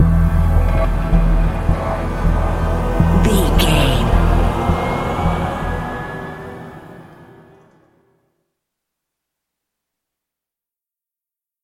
Thriller
Aeolian/Minor
E♭
Slow
synthesiser